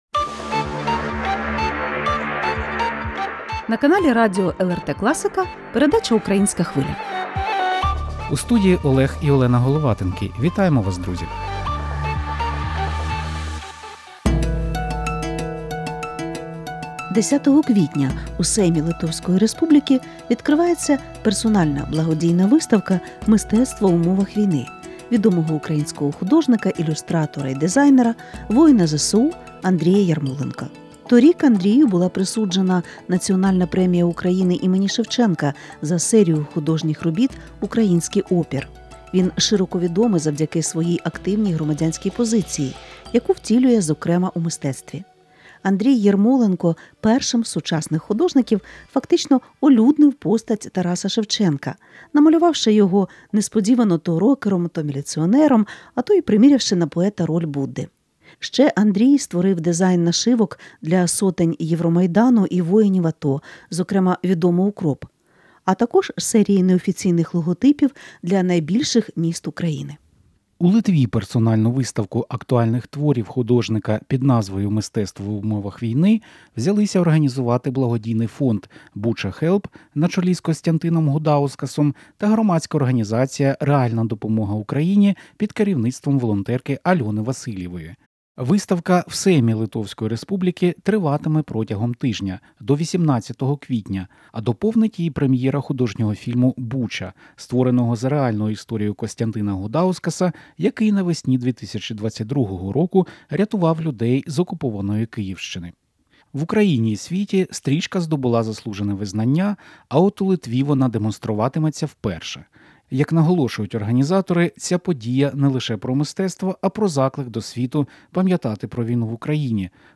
В інтерв’ю з художником та волонтером говоримо про дієву силу мистецтва та як воно спроможне протистояти ворожій пропаганді.